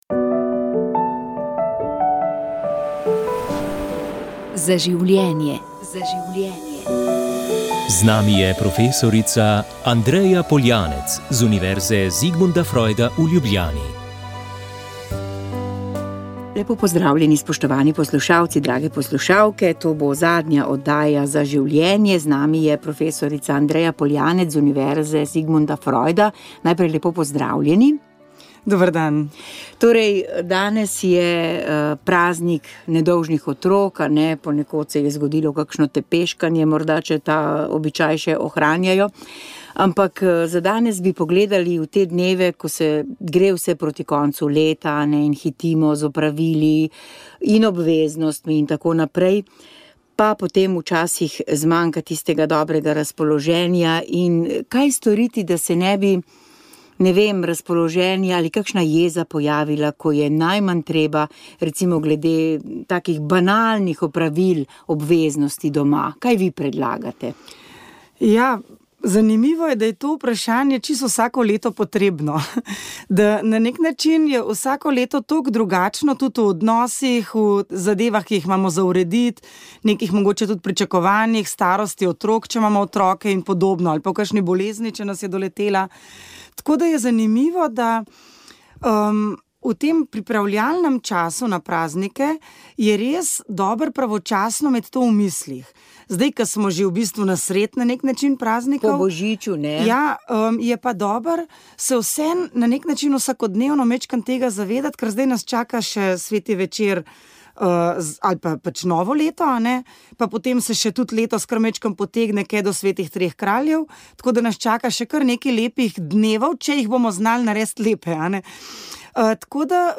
Na svetovni dan beguncev smo spregovorili o migracijah, predvsem nezakonitih, pa o tem, kako je Slovenija ob drugem valu pripravljena na to, ter – ali begunci in migranti predstavljajo velik varnostni problem - ali je strah lokalnega prebivalstva upravičen. Gostje: Boštjan Šefic, državni sekretar na MNZ, mag. Mojca Špec Potočar, direktorica vladnega urada za oskrbo in integracijo migrantov, mag. Maja Kocjan, Civilna iniciativa proti migrantskemu centru v Beli krajini in črnomaljska občinska svetnica.